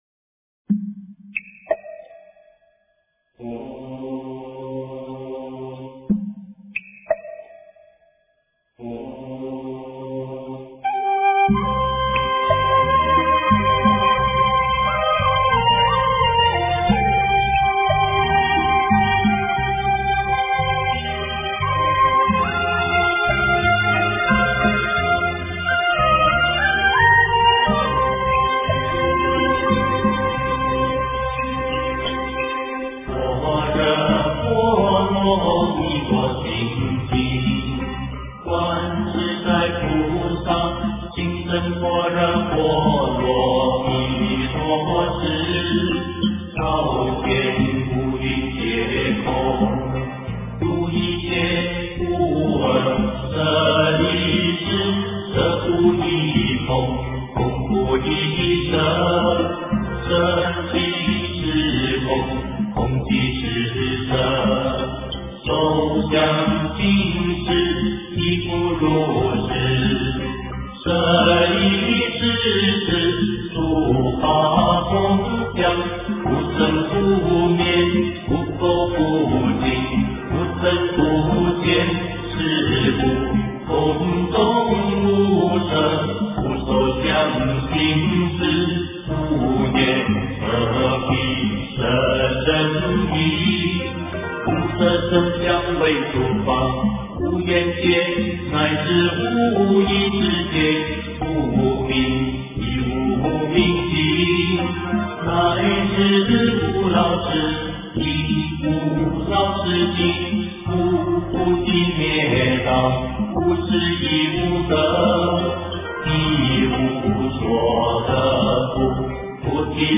大悲神咒 诵经 大悲神咒--佚名 点我： 标签: 佛音 诵经 佛教音乐 返回列表 上一篇： 般若波罗蜜多心经 下一篇： 释迦牟尼佛传45 相关文章 不动明王真言--佚名 不动明王真言--佚名...